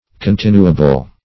Continuable \Con*tin"u*a*ble\, a.